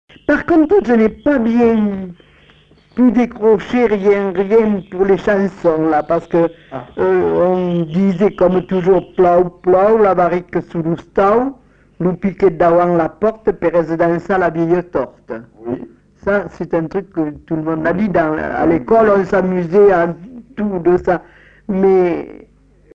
Aire culturelle : Bazadais
Lieu : Grignols
Genre : forme brève
Type de voix : voix de femme
Production du son : lu
Classification : formulette enfantine